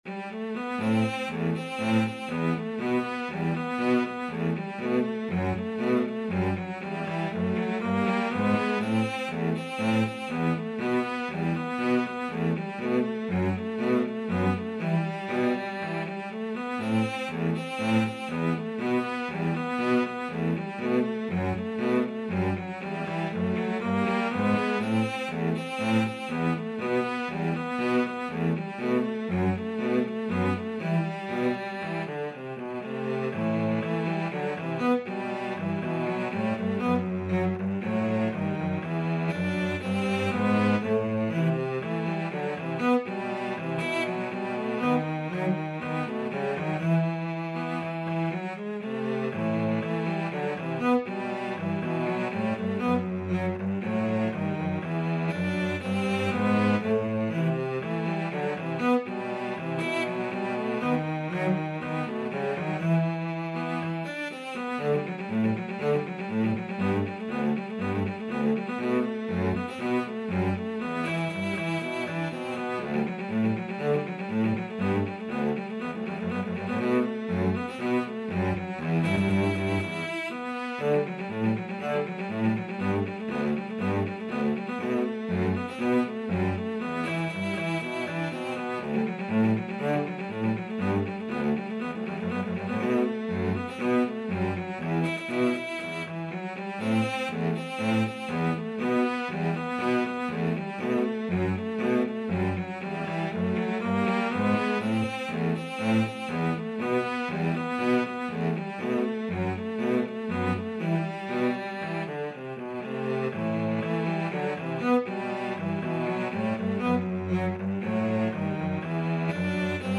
Cello 1Cello 2
E minor (Sounding Pitch) (View more E minor Music for Cello Duet )
Fast Two in a Bar =c.120